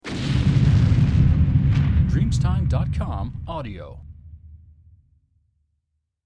Explosion 002